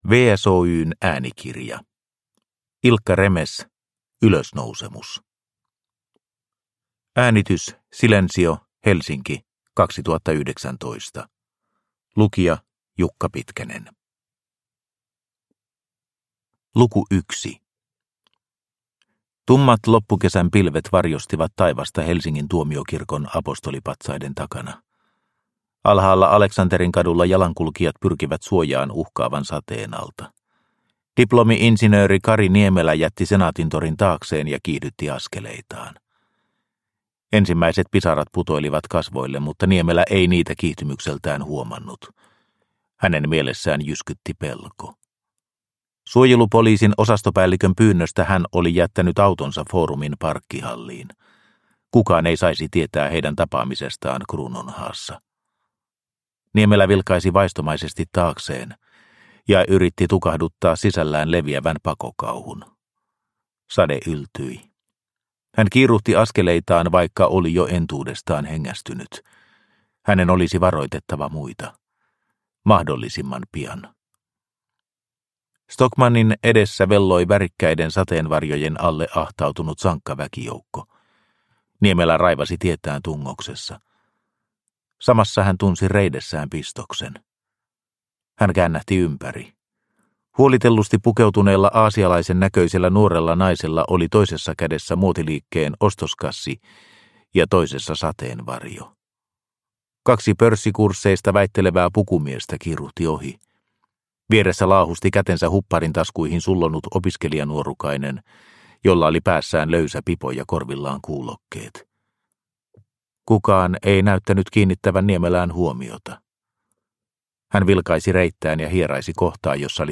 Ylösnousemus – Ljudbok – Laddas ner